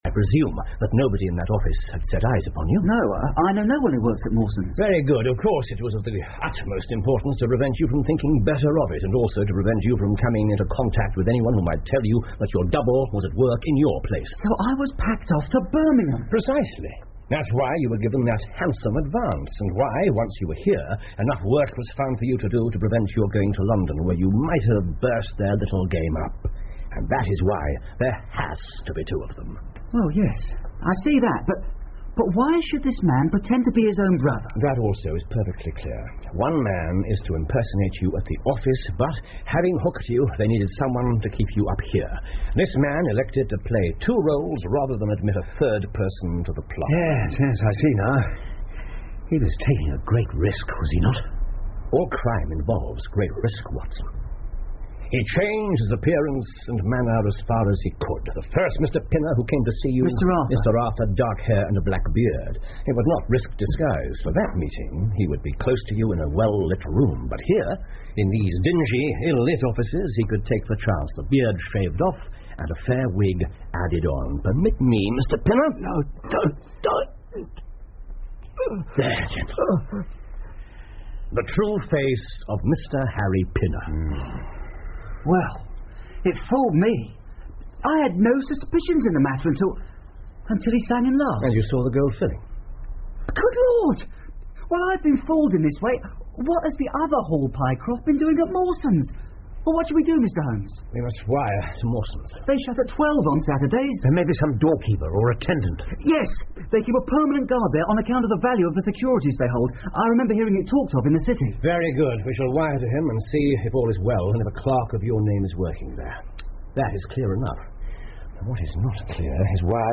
福尔摩斯广播剧 The Stock Brokers Clerk 8 听力文件下载—在线英语听力室